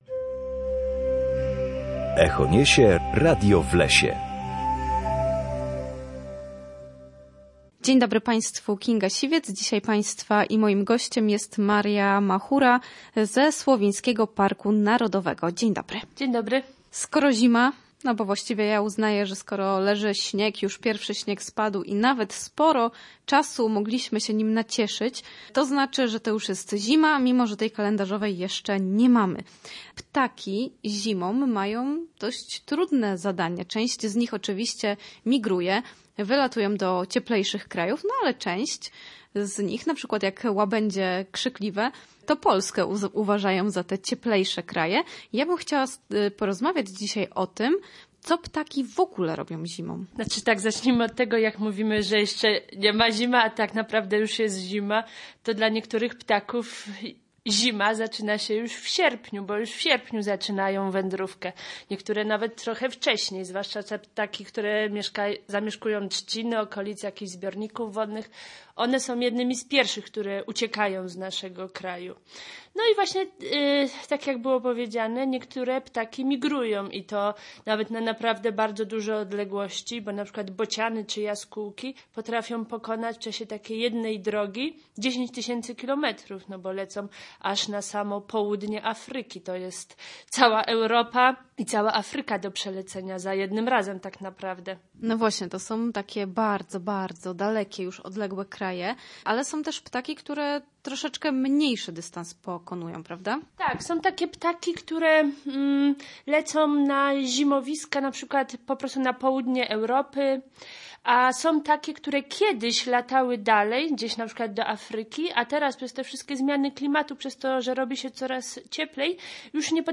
W każdą środę o godzinie 7:20 oraz o godzinie 14:10 na antenie Studia Słupsk rozmawiamy o naturze i sprawach z nią związanych.